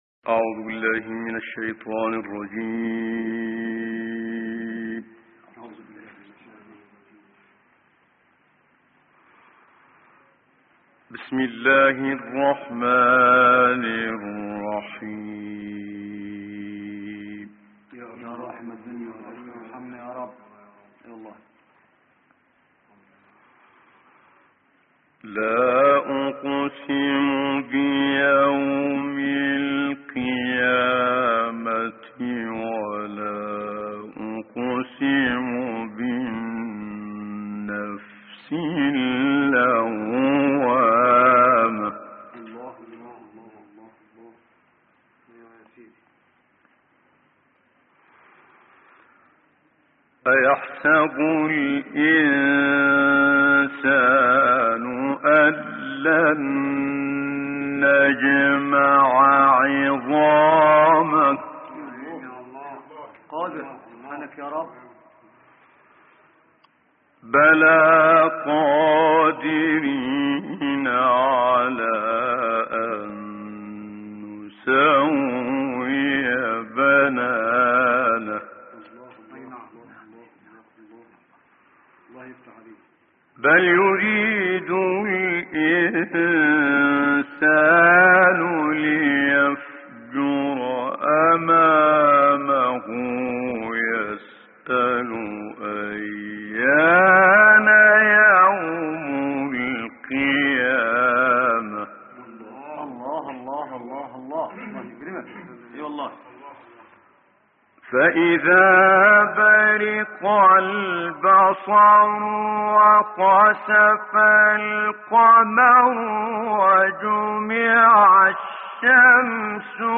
دانلود قرائت سوره های قیامت و انسان آیات 1 تا 20 - استاد متولی عبدالعال